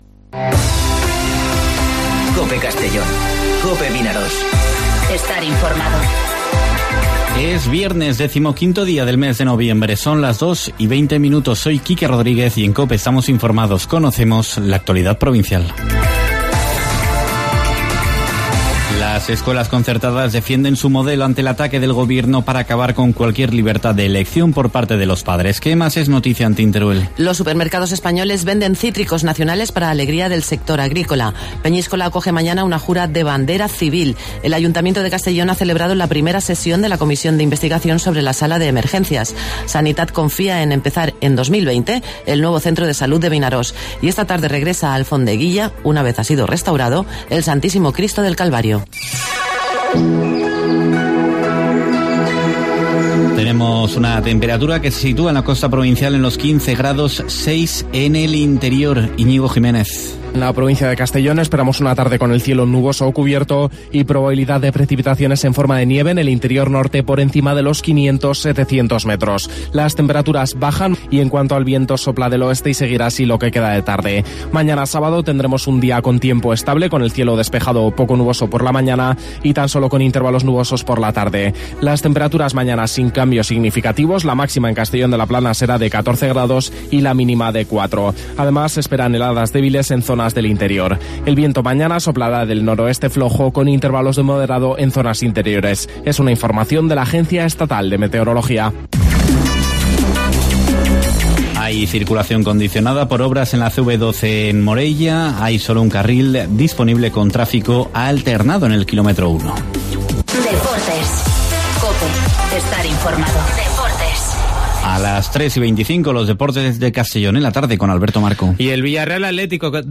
Informativo Mediodía COPE en Castellón (15/11/2019)